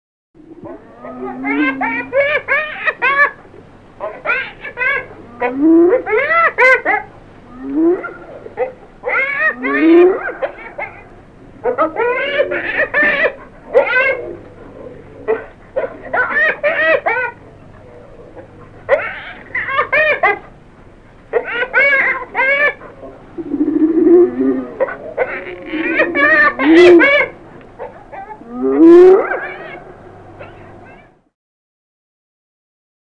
elle hurle et ricane
par La hyène
hyene.mp3